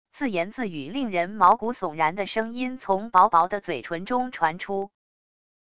自言自语令人毛骨悚然的声音从薄薄的嘴唇中传出wav音频